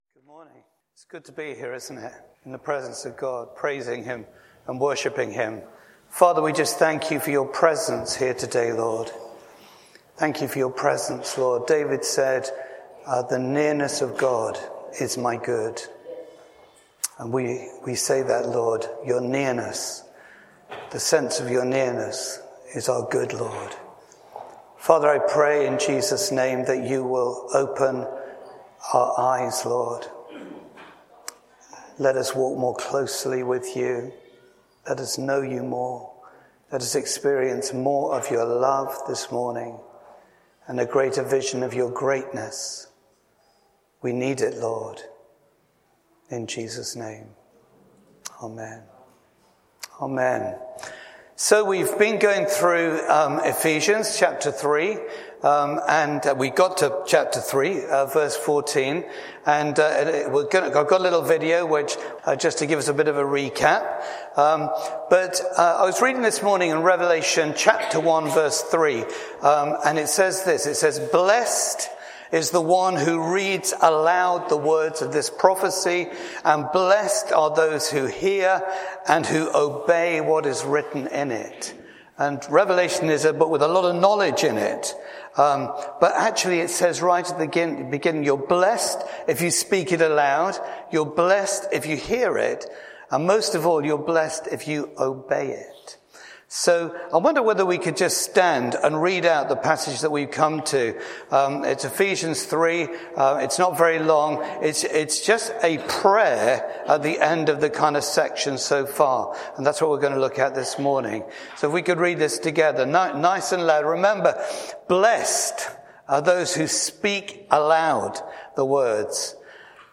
We would know Him better, Bassett Street Sermons Download